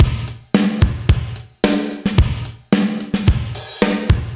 AM_GateDrums_110-01.wav